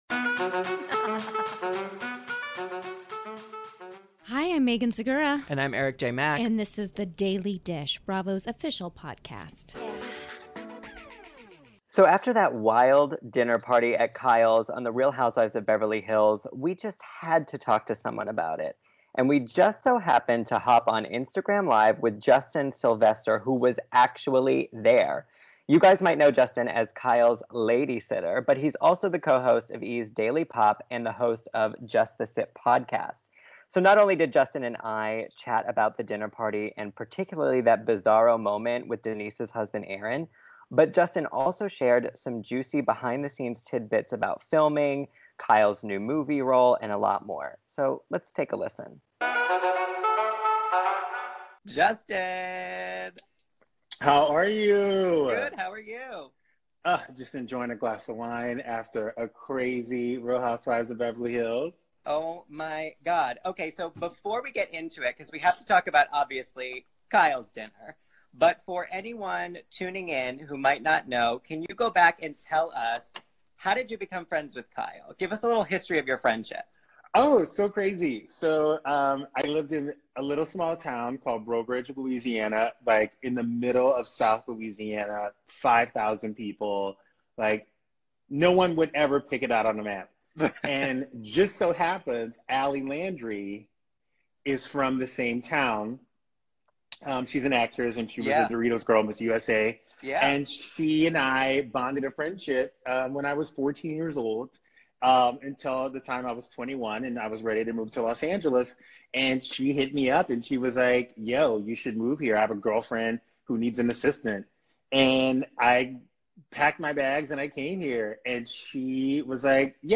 Justin Sylvester Explains THAT Kyle Richards RHOBH Dinner Party (Instagram Live)